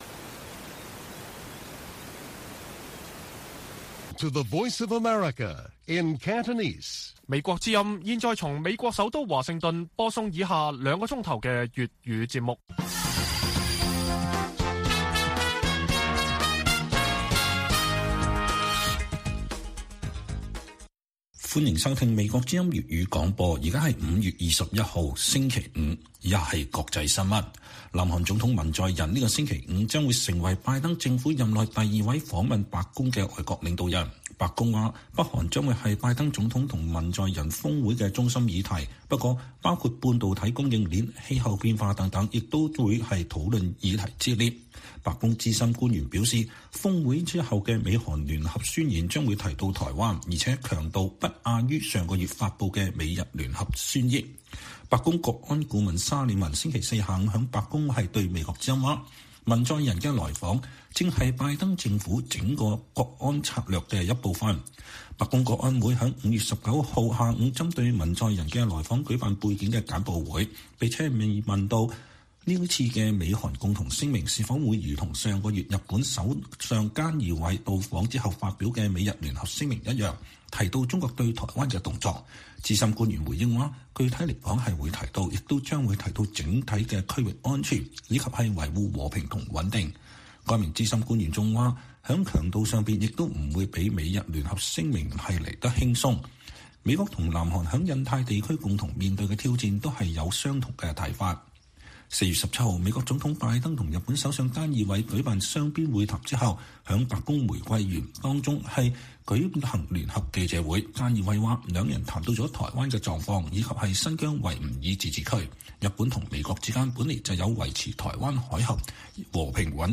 粵語新聞 晚上9-10點: 北韓將是美韓峰會中心議題，美韓聯合宣言將提台灣